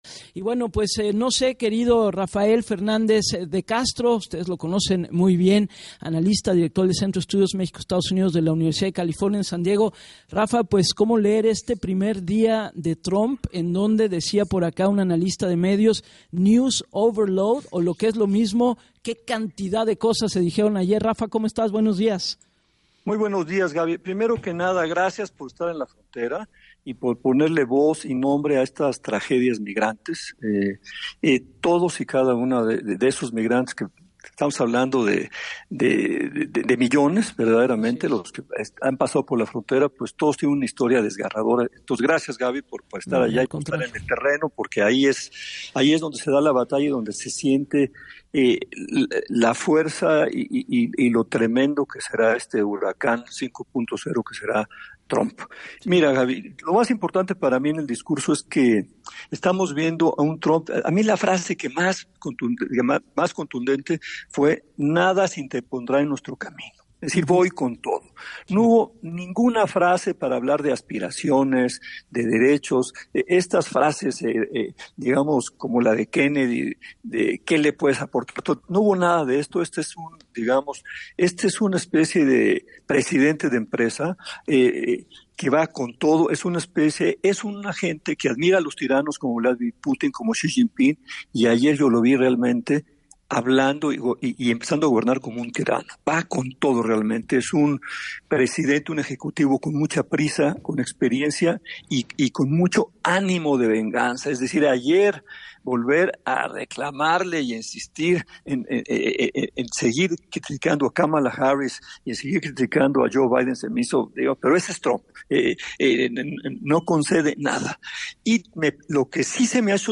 En entrevista para “Así las Cosas” con Gabriela Warkentin, dijo que Trump es “una especie de presidente de empresa y ayer empezó a gobernar como un tirano”; insistió en criticar a Biden y Kamala, y “comenzó a sentirse emperador”, señaló el analista.